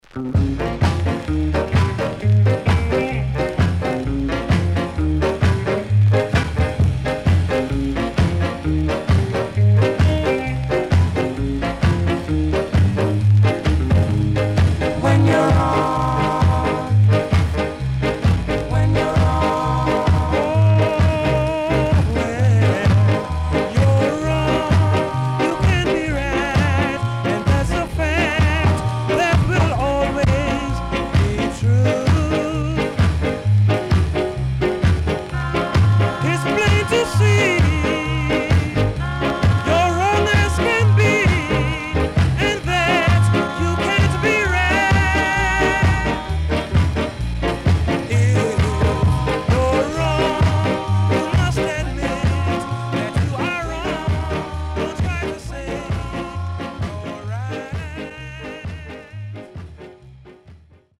SIDE A:所々チリノイズがあり、少しプチノイズ入ります。